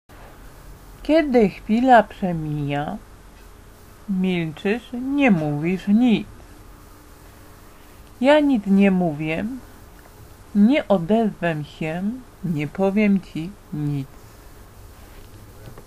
Audiobook z wierszykiem "Milczenie" Słuchaj